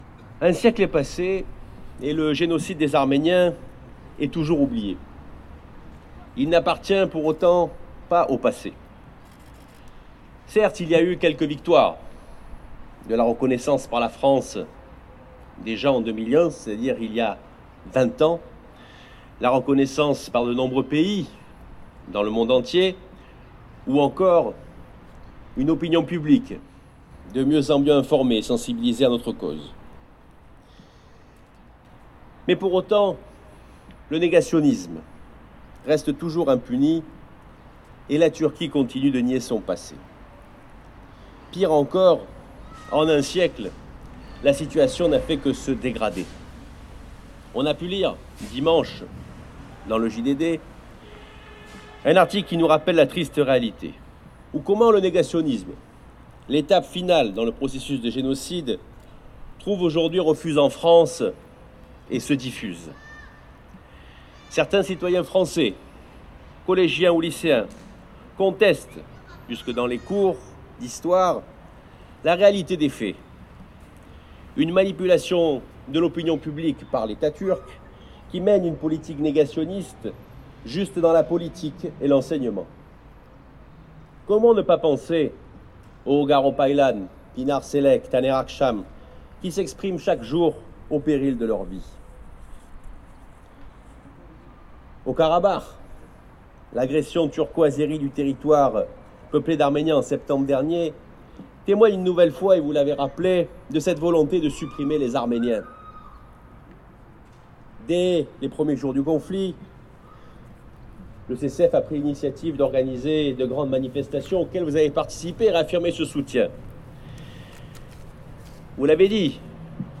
Intervention de